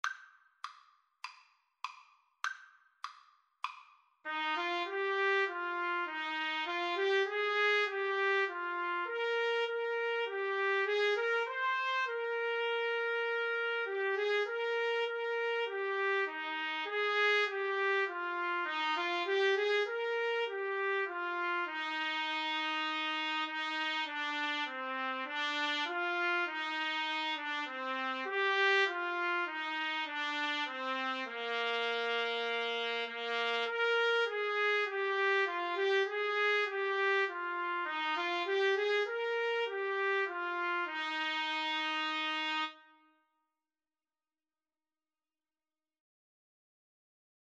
Eb major (Sounding Pitch) F major (Trumpet in Bb) (View more Eb major Music for Trumpet Duet )
4/4 (View more 4/4 Music)
Trumpet Duet  (View more Easy Trumpet Duet Music)
Classical (View more Classical Trumpet Duet Music)